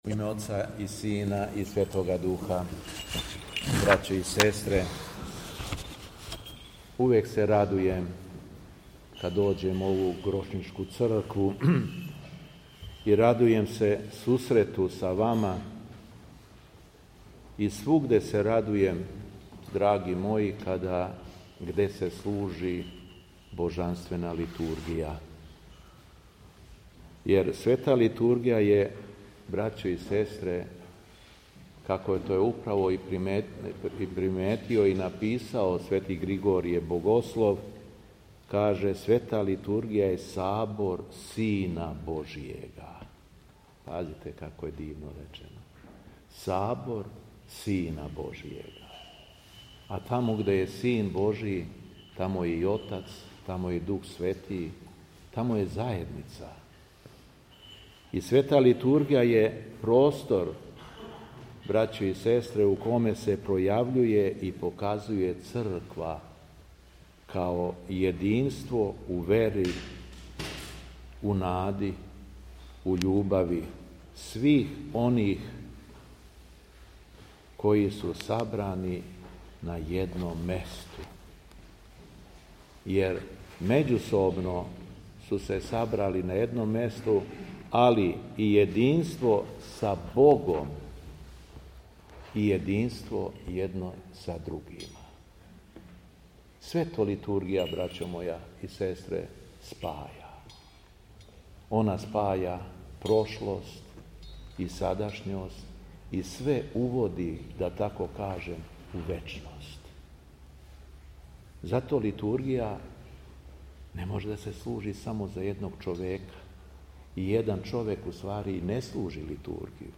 СВЕТА АРХИЈЕРЕЈСКА ЛИТУРГИЈА У ГРОШНИЦИ
Беседа Његовог Високопреосвештенства Митрополита шумадијског г. Јована